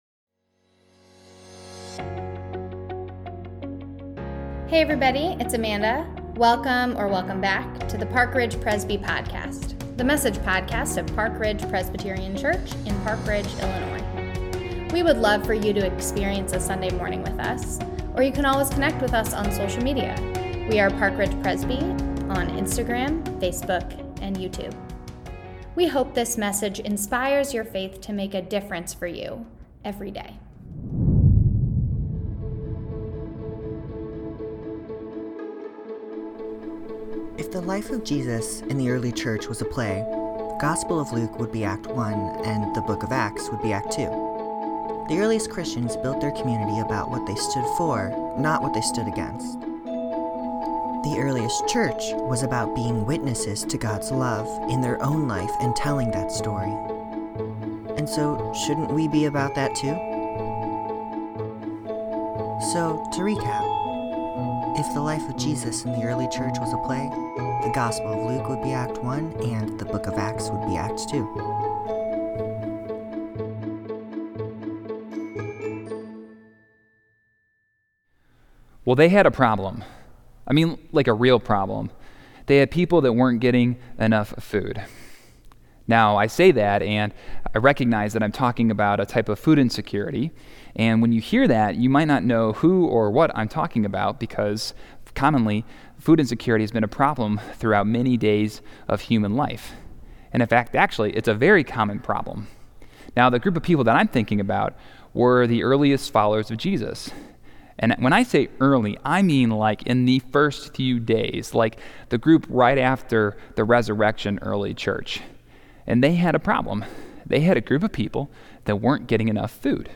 Sermon-Audio.mp3